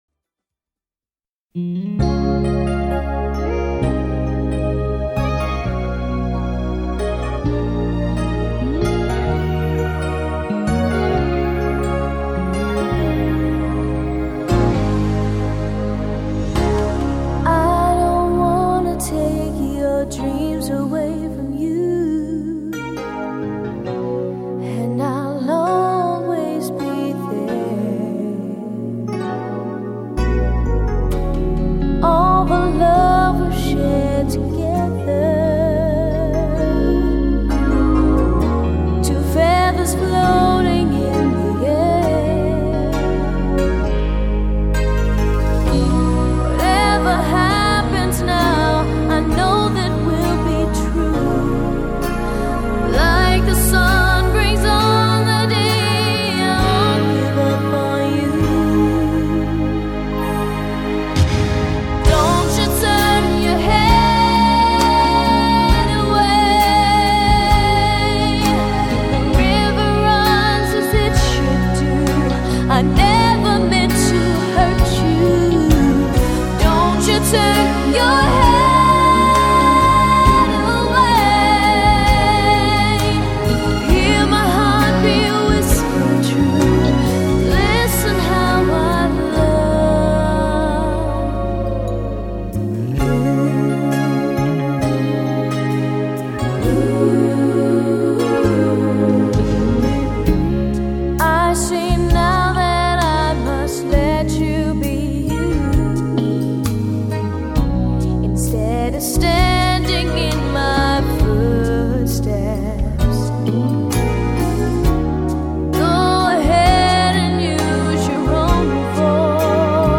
Genre: Pop-Rock.